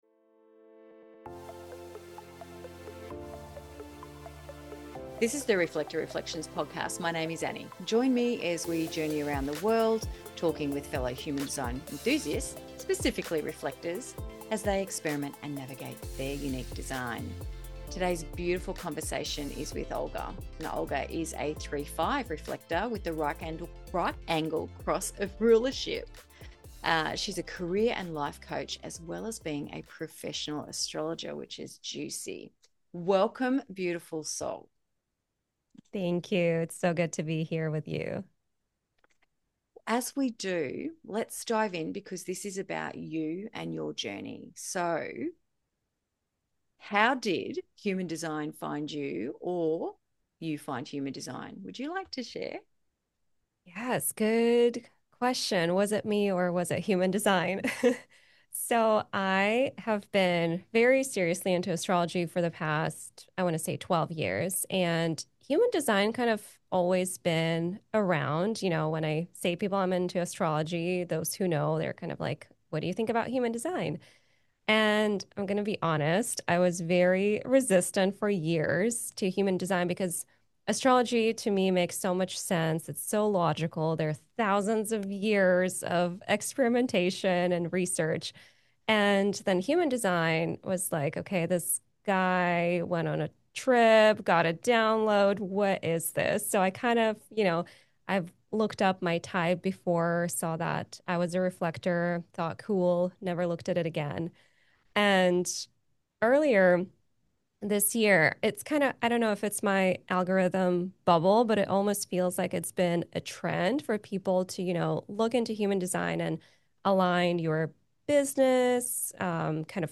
Episode 113 – Today’s episode is a grounded, honest conversation